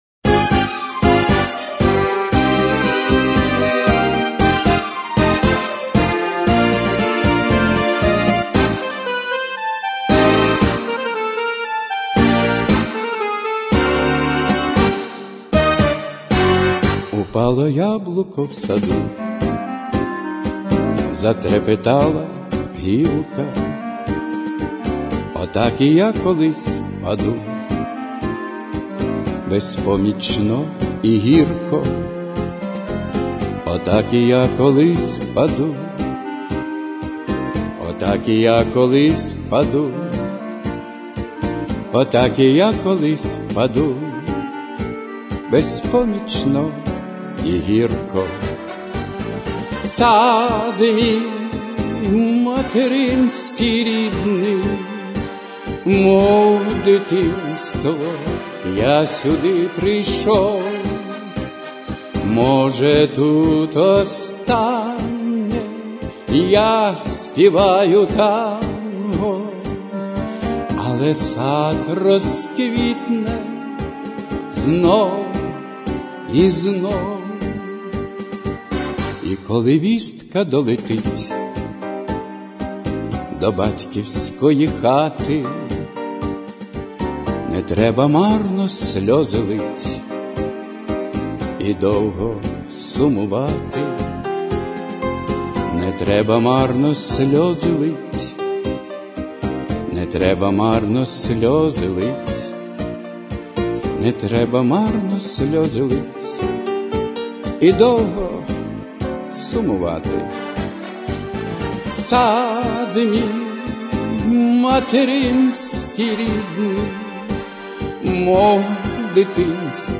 Всі мінусовки жанру Танго
Плюсовий запис